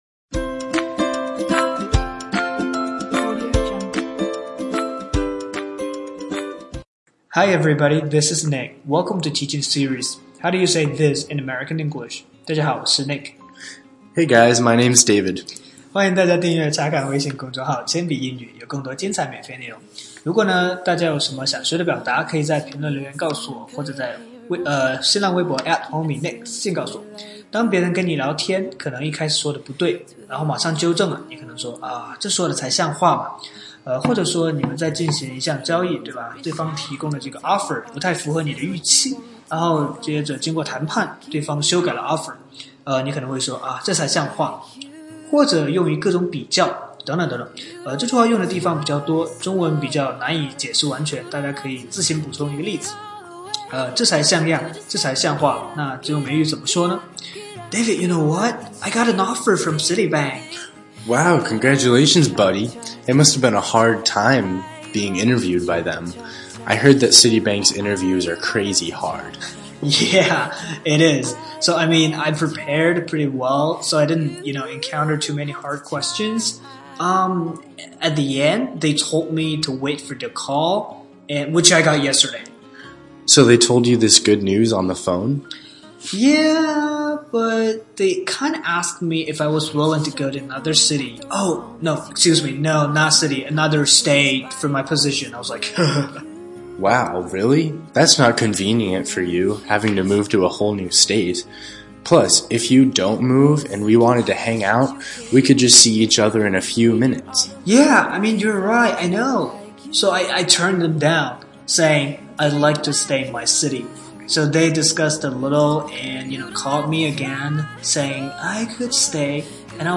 在线英语听力室全网最酷美语怎么说:第48期 这才像样的听力文件下载, 《全网最酷美语怎么说》栏目是一档中外教日播教学节目，致力于帮大家解决“就在嘴边却出不出口”的难题，摆脱中式英语，学习最IN最地道的表达。